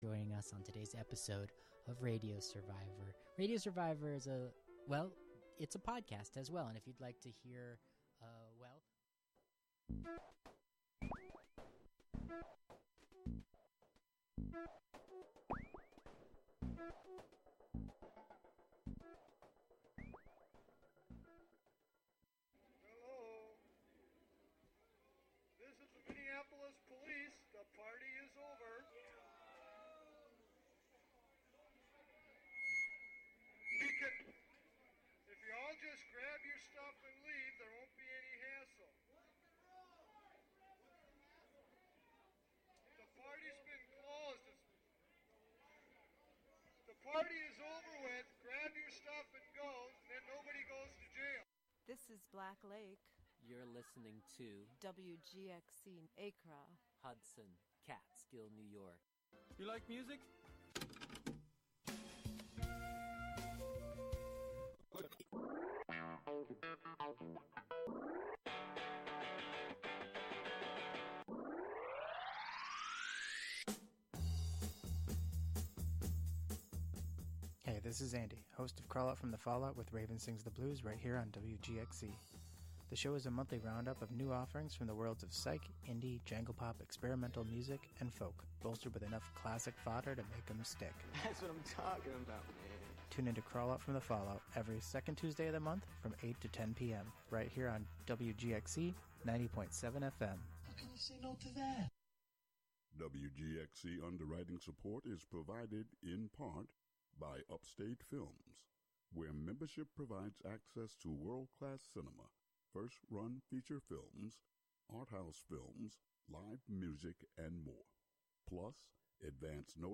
To extend this idea into practice, other non-human forms and systems will co-host the show, speak, and sing.